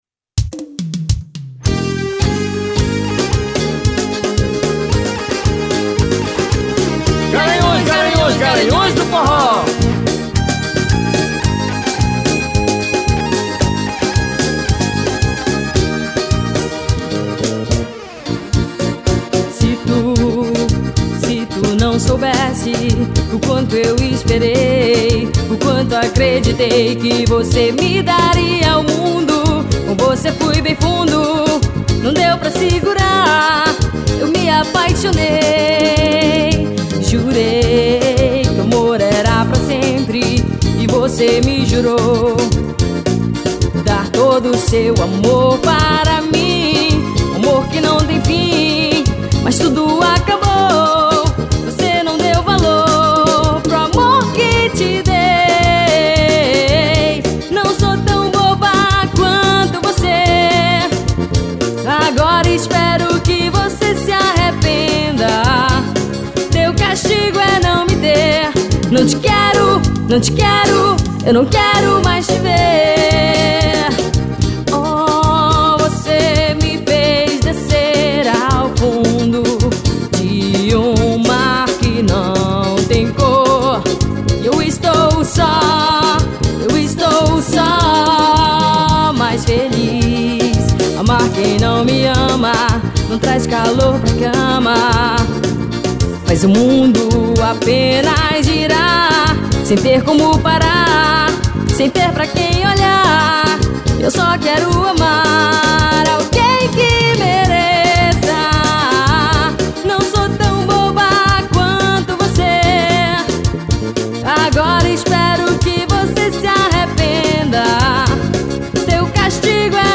EstiloForró